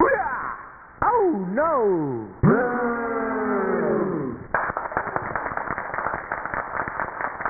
My personal favorite laugh:
The creepiest laugh ever: